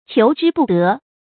注音：ㄑㄧㄡˊ ㄓㄧ ㄅㄨˋ ㄉㄜˊ
求之不得的讀法